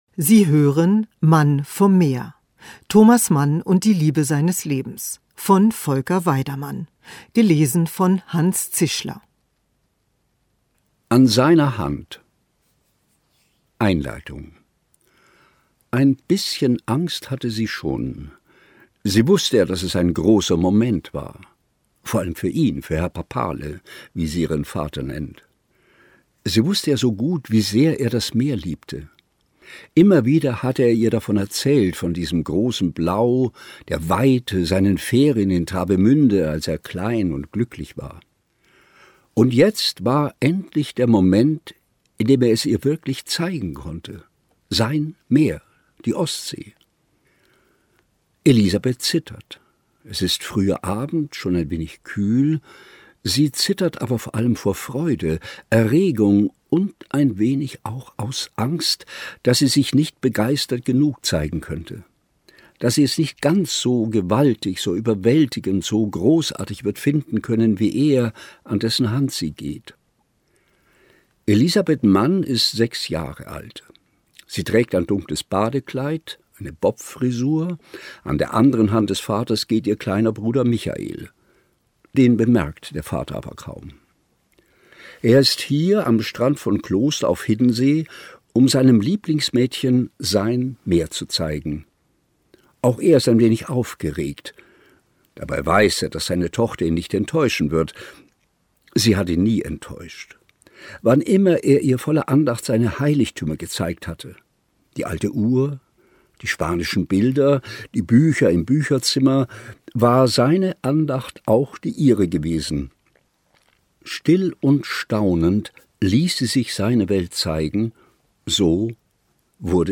Ungekürzte Lesung mit Hanns Zischler (1 mp3-CD)
Hanns Zischler (Sprecher)